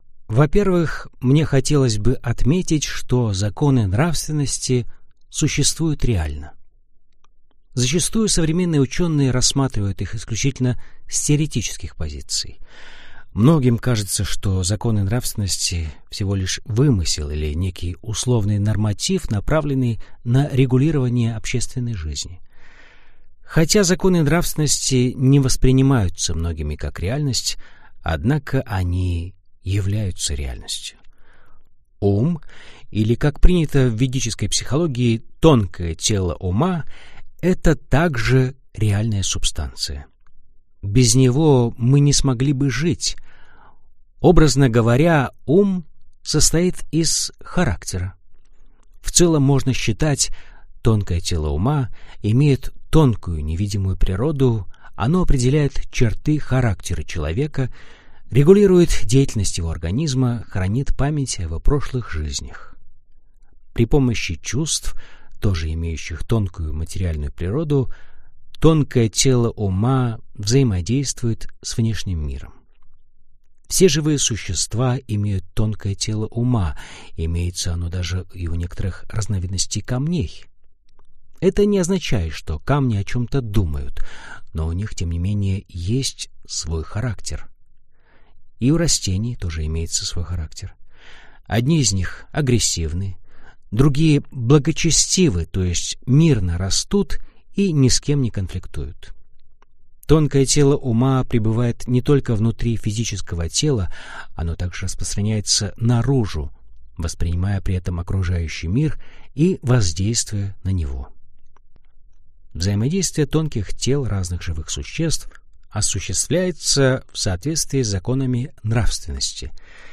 Аудиокнига Сила характера – ваш успех | Библиотека аудиокниг